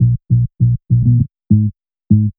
FK100BASS2-R.wav